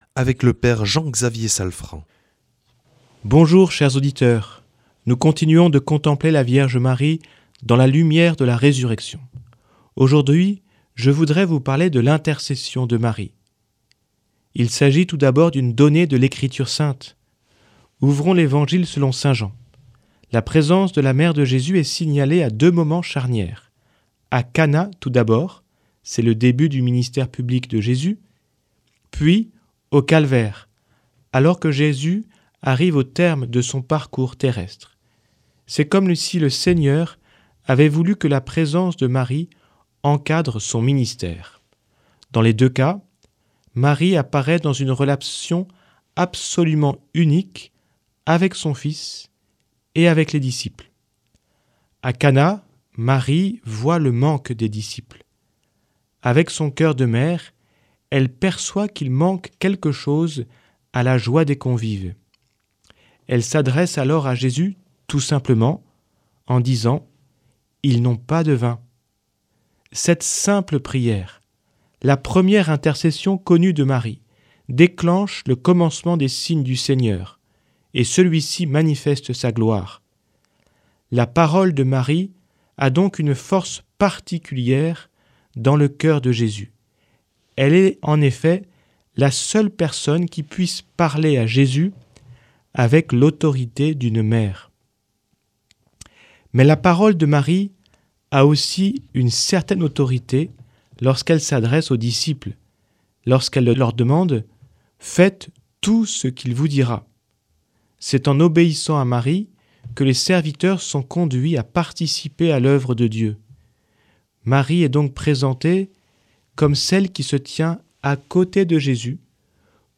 Enseignement Marial du 23 avr.